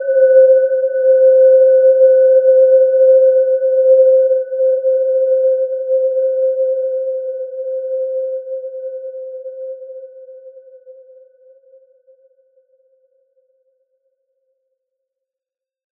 Gentle-Metallic-3-C5-mf.wav